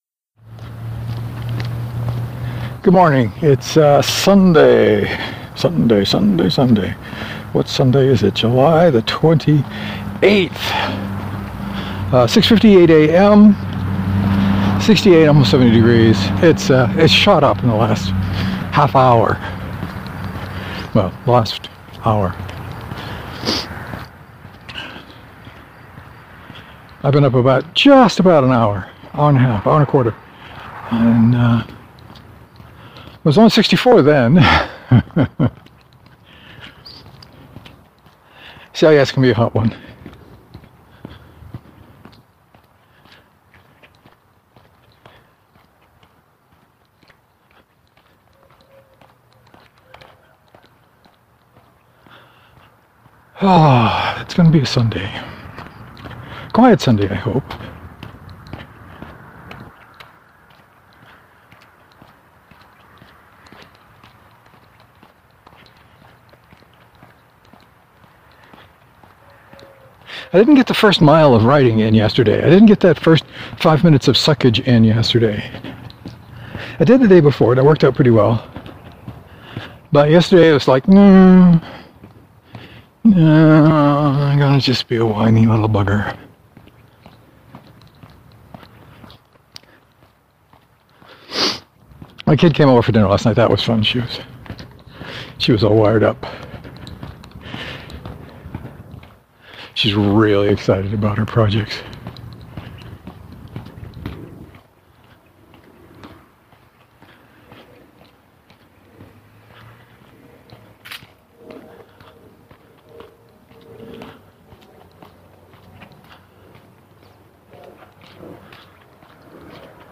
Lots of boot noise today.